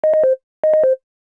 LowBattery.aac